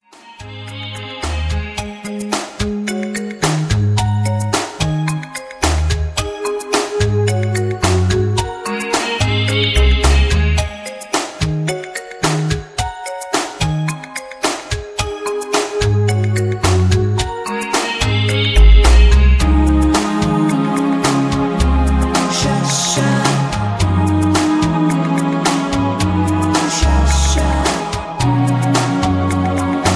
(Version-1, Key-Ab) Karaoke MP3 Backing Tracks
mp3 backing tracks